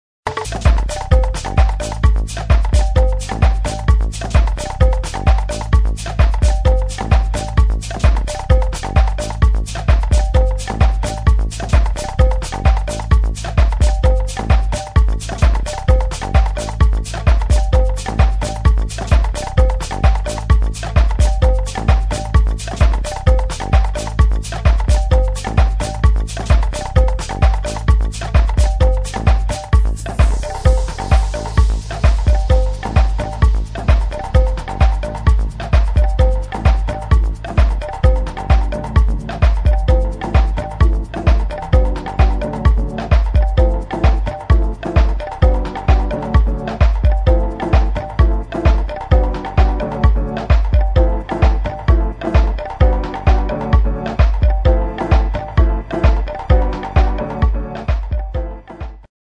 [ AFRO BEAT / WORLD / DEEP HOUSE ]